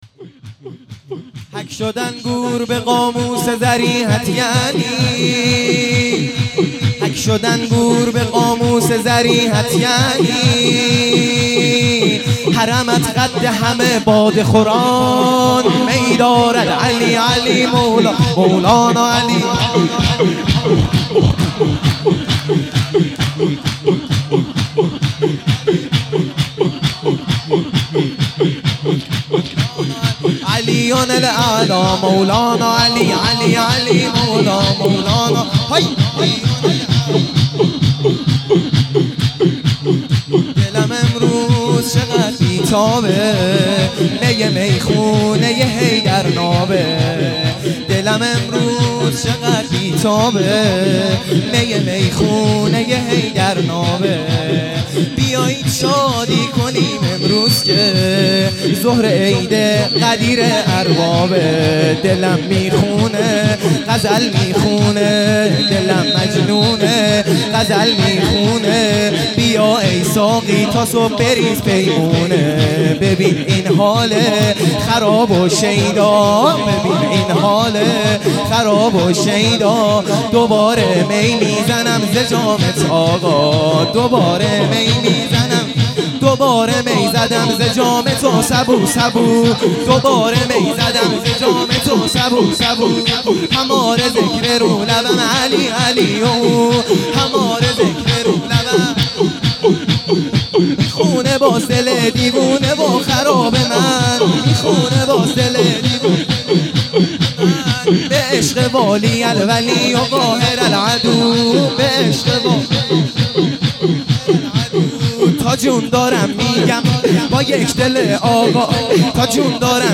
سرود
جشن عید غدیر خم